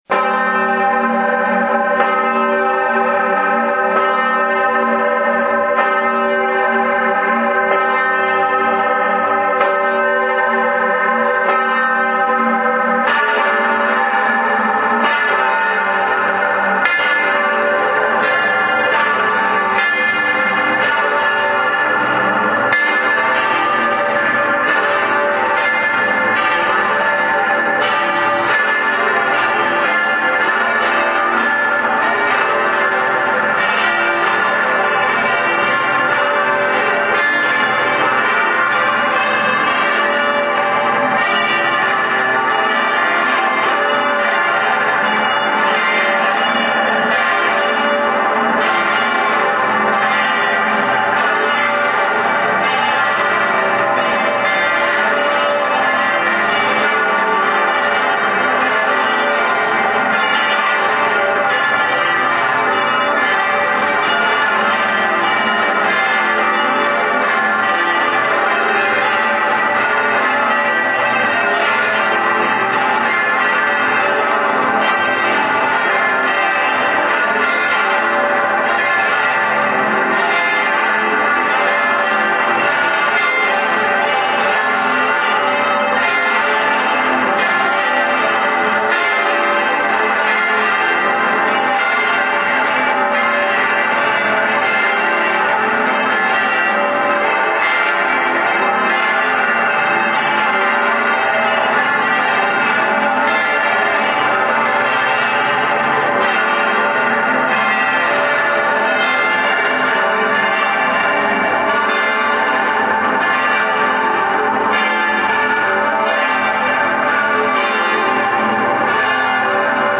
alle Glocken der Hl. Kreuzkirche Lana